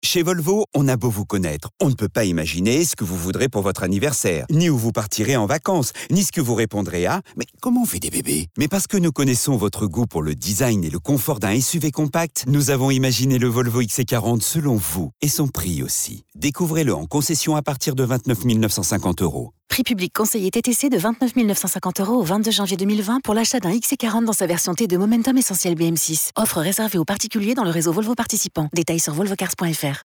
Voix off
35 - 55 ans - Baryton